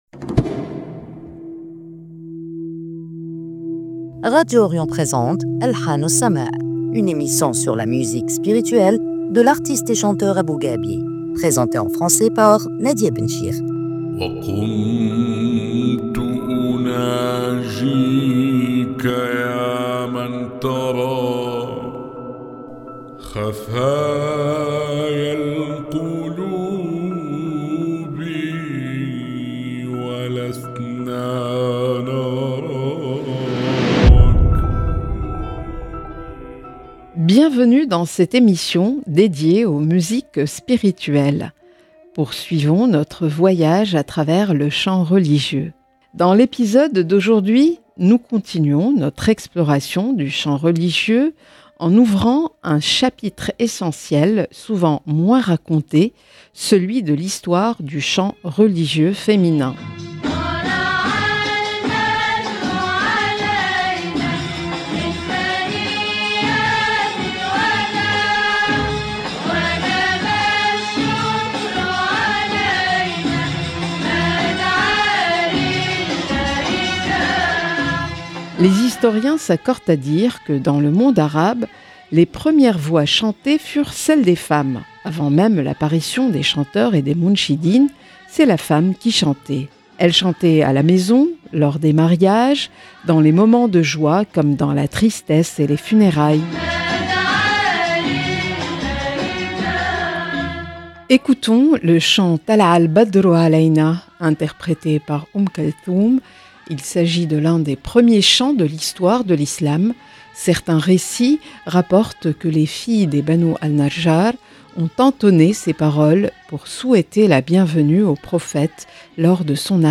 une émission sur la musique spirituelle